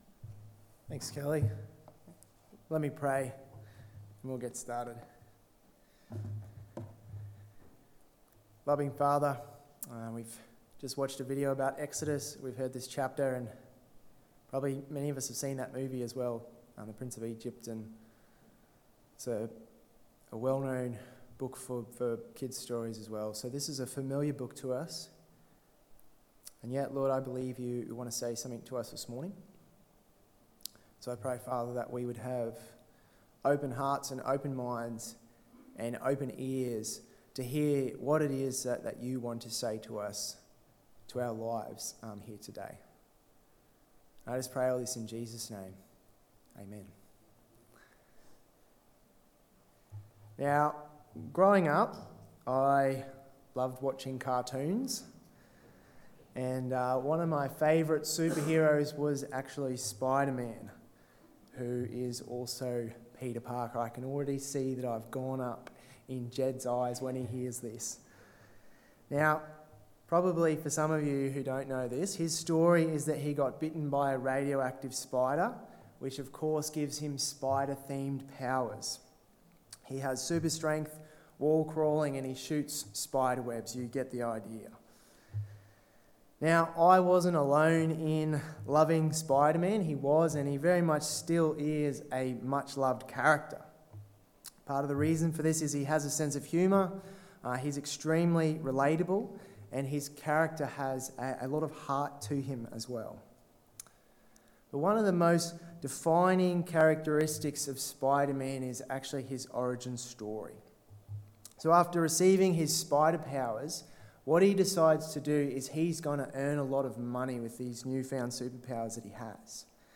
Setting the Scene (Exodus 1 Sermon) 30/04/2023